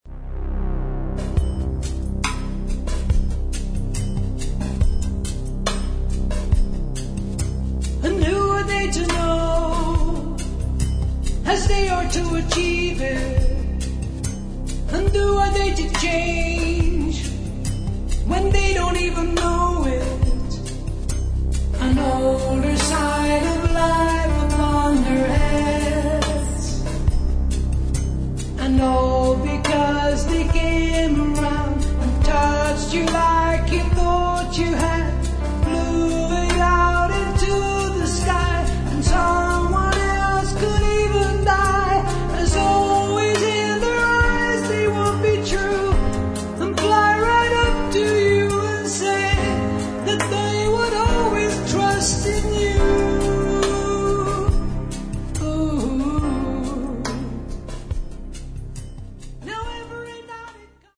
Рок
На альбоме два вокала
В любом случае, качество записи на высоте.